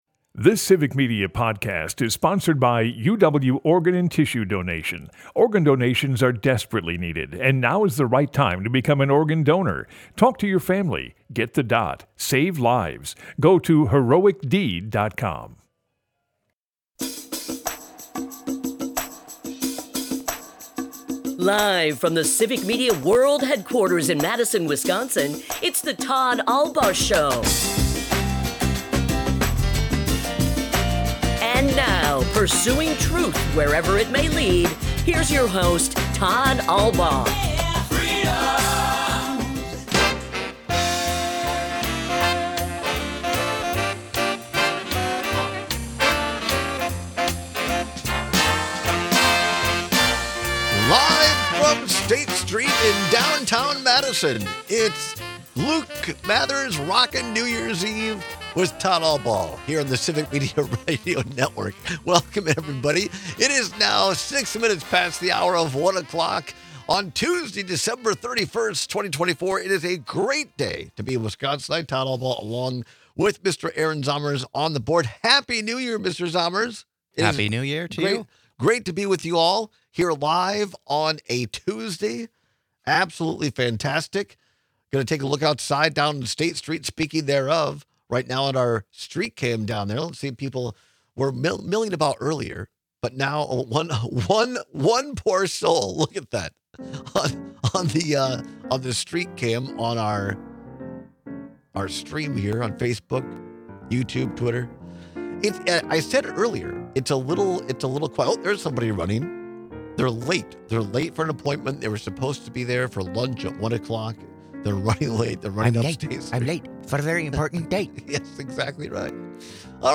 We take your calls and texts with opinions.&nbsp